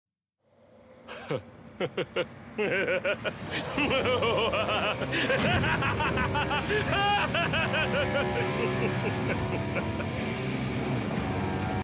Dios insane laughter
dios-insane-laughter.mp3